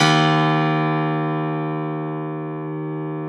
53f-pno03-D0.aif